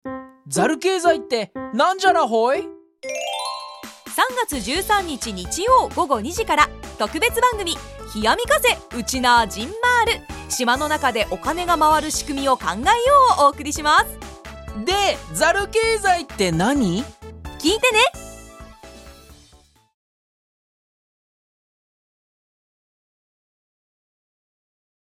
番組宣伝CM・果報庭開催について